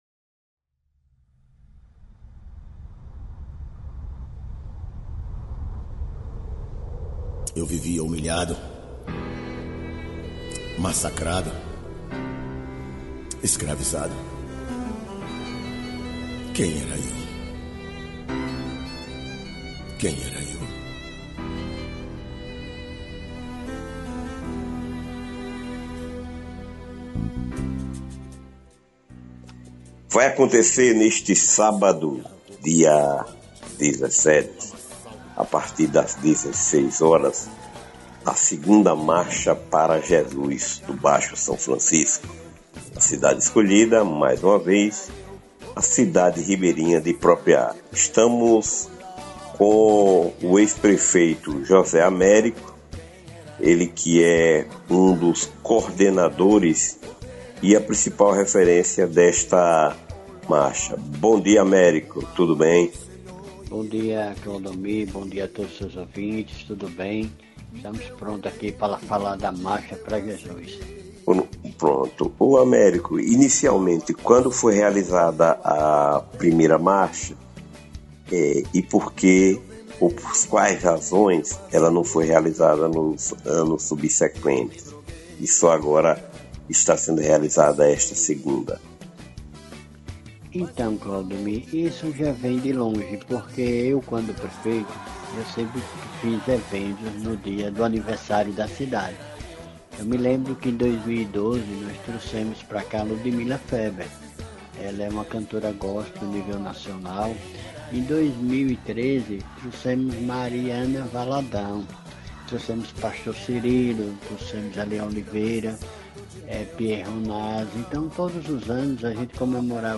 É amanhã: 2ª Marcha Para Jesus do Baixo São Francisco vai acontecer em Propriá – Confira entrevista